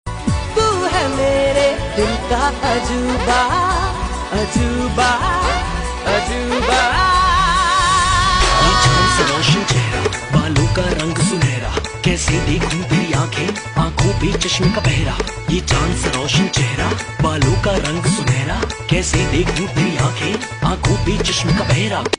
This catchy ringtone